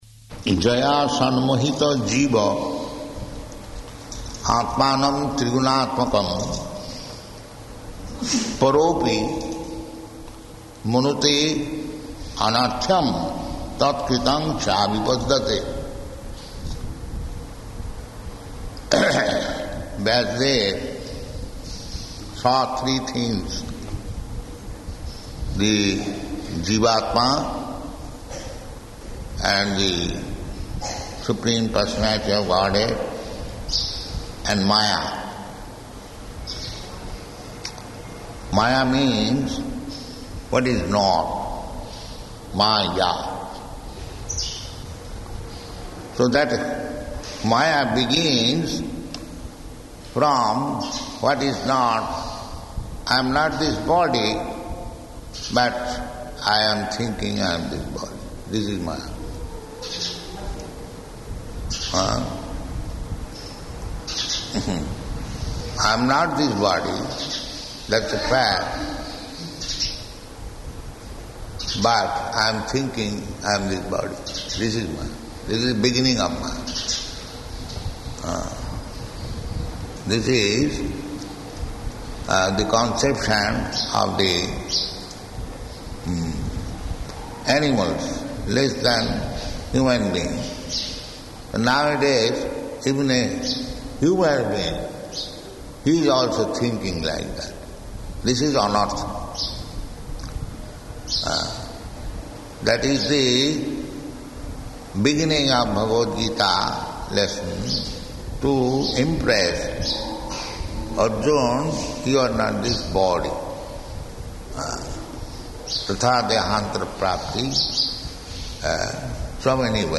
Type: Srimad-Bhagavatam
Location: Vṛndāvana